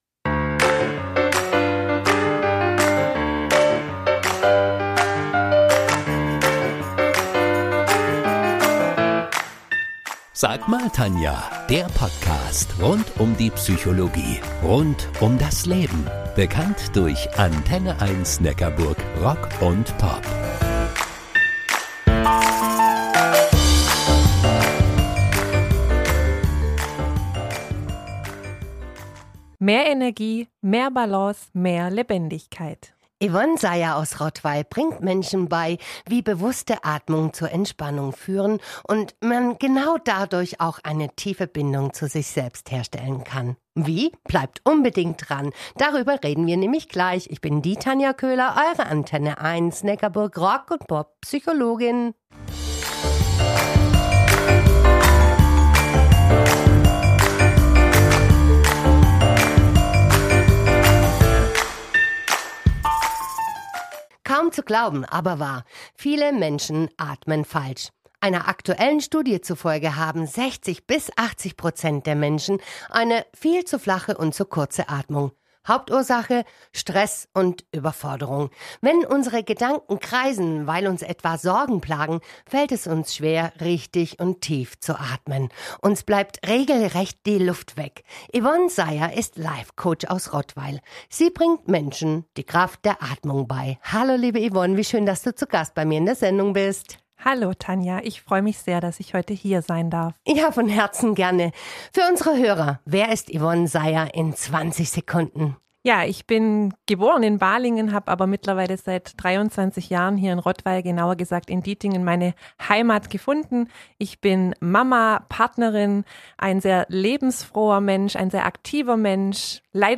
Podcast-Episode ist ein Mitschnitt der Original-Redebeiträge meiner